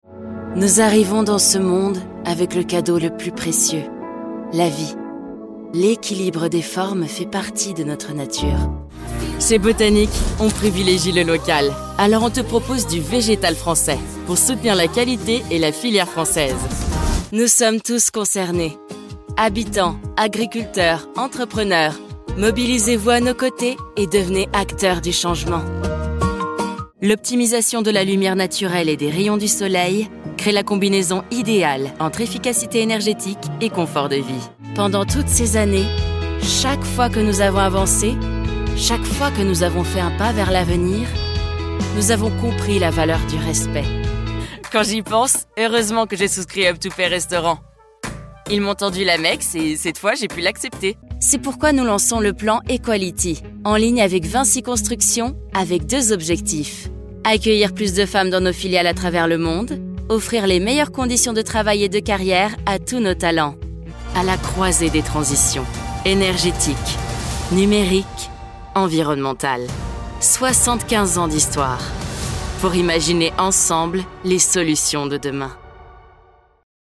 Voix
Naturelle, Polyvalente, Amicale
Corporate